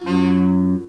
F#